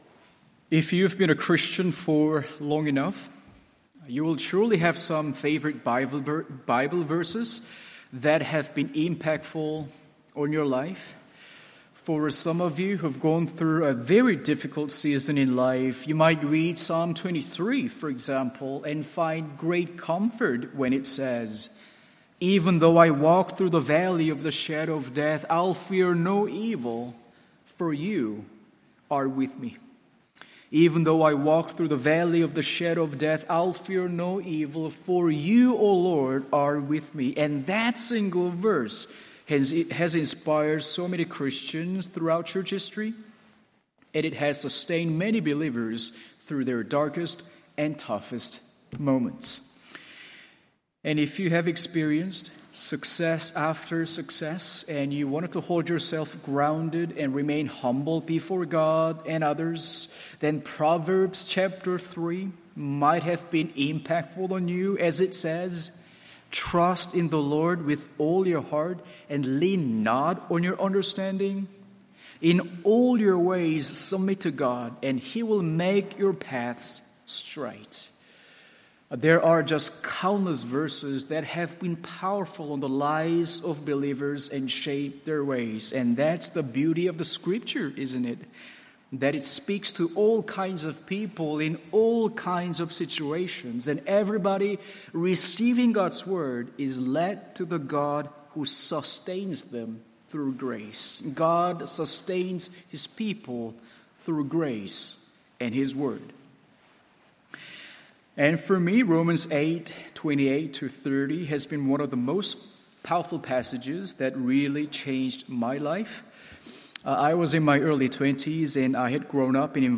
MORNING SERVICE…